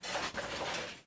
machine_walk5.wav